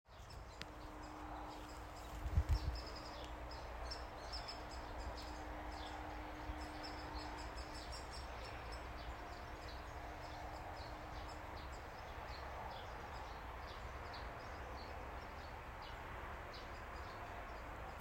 Птицы -> Дятловые ->
малый пестрый дятел, Dryobates minor
СтатусВзволнованное поведение или крики